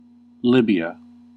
Ääntäminen
Ääntäminen US Tuntematon aksentti: IPA : /ˈlɪbiə/ Haettu sana löytyi näillä lähdekielillä: englanti Käännös Erisnimet 1.